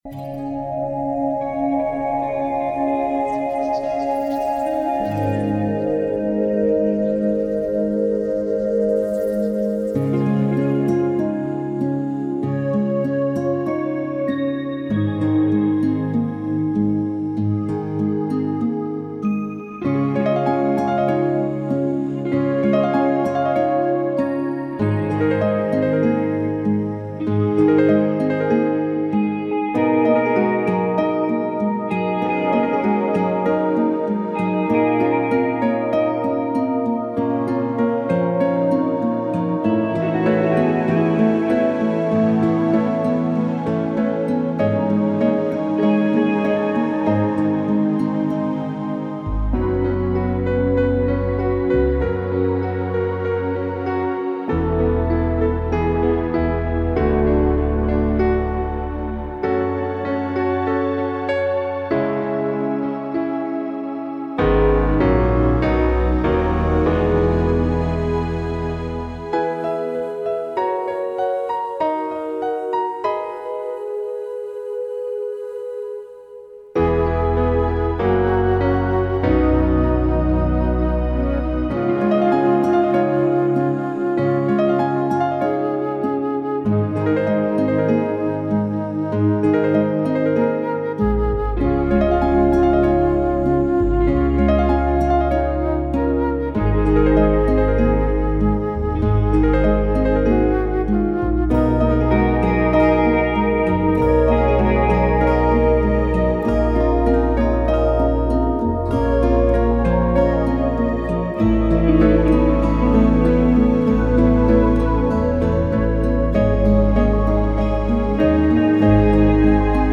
This medley of lush song arrangements convey peacefulness.
peaceful-inspired-arrangement-gb-n.mp3